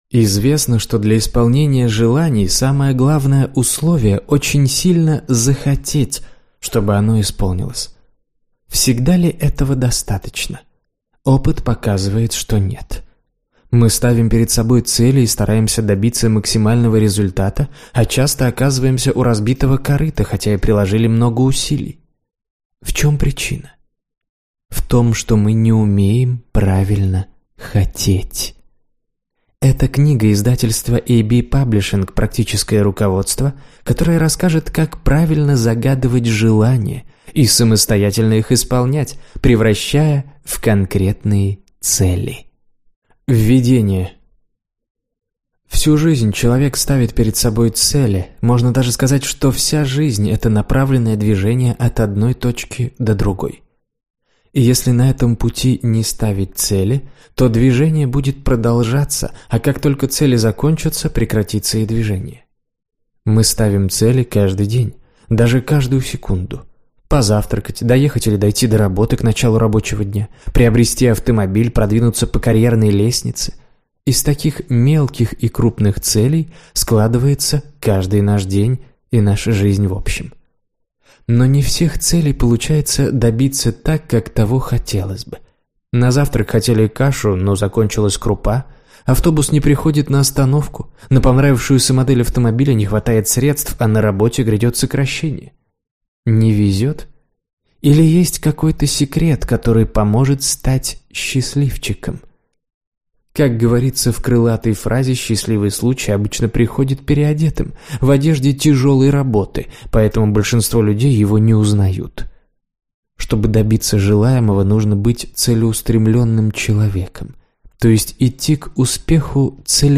Аудиокнига Вижу цель: как понять, чего я хочу | Библиотека аудиокниг